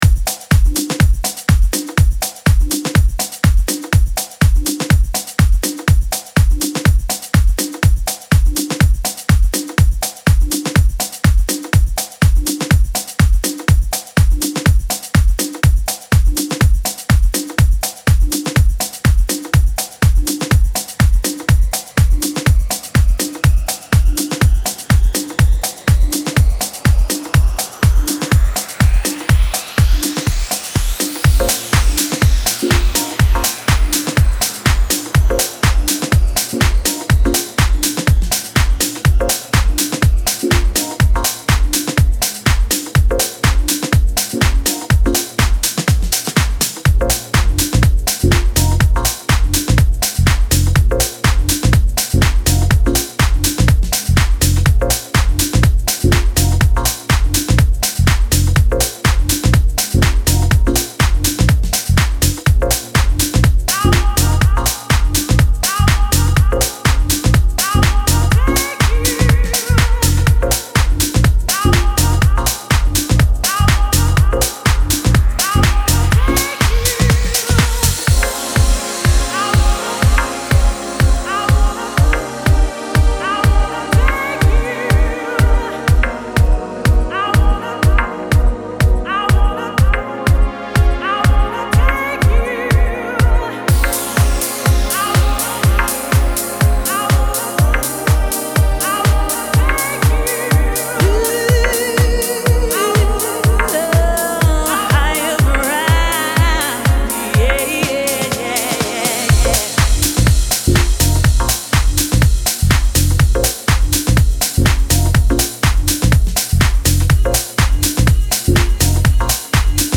Dub Mix
123 Jazzy Soulful House